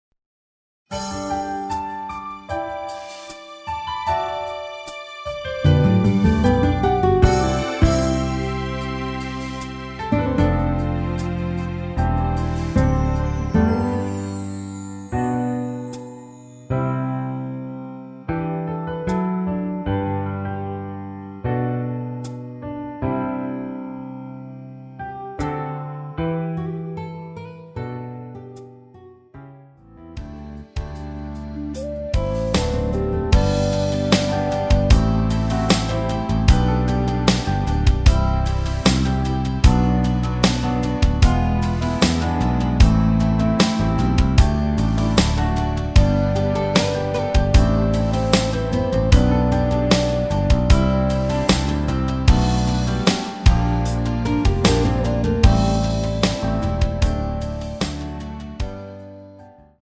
두명이서 부른 키 입니다.
원곡의 보컬 목소리를 MR에 약하게 넣어서 제작한 MR이며